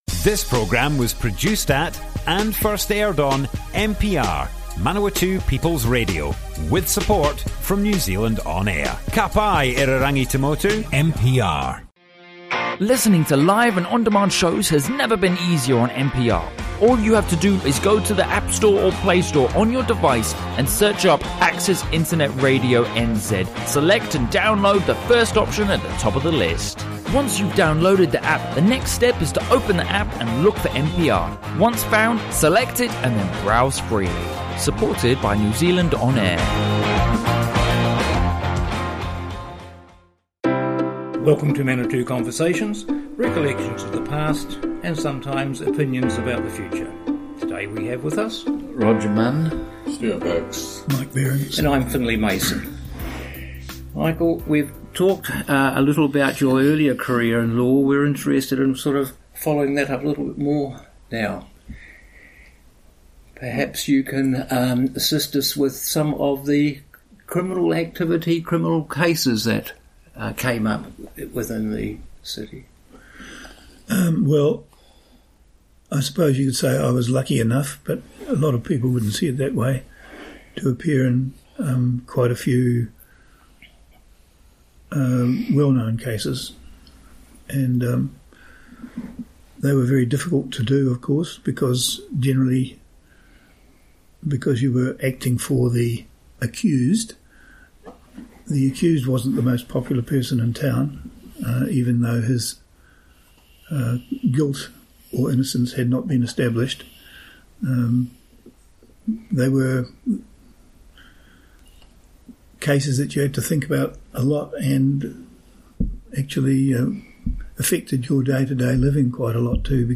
00:00 of 00:00 Add to a set Other Sets Description Comments Mike Behrens, lawyer, judge - Manawatu Conversations More Info → Description Broadcast on Manawatu People's Radio 9 November 2018.
oral history